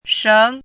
chinese-voice - 汉字语音库
sheng2.mp3